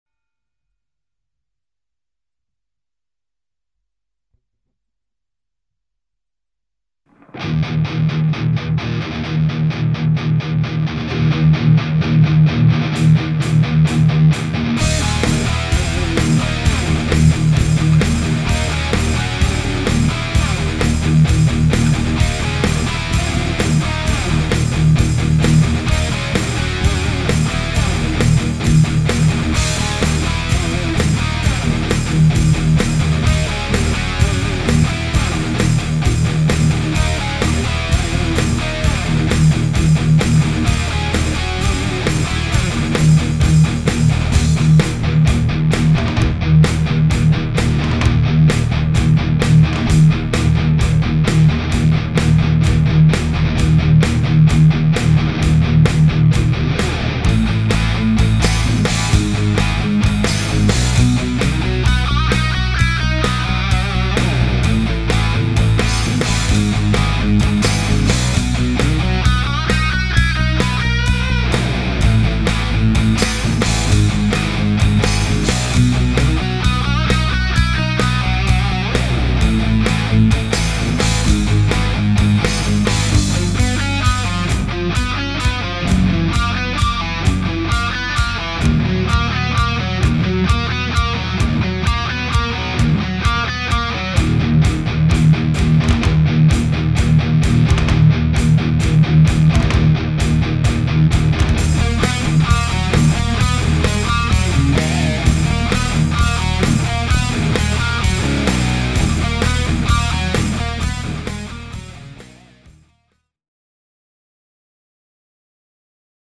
Dernier prix (1000€) ou échange : tête NOS CA 50w el34 - Ampli guitare
sampleheavymetal.mp3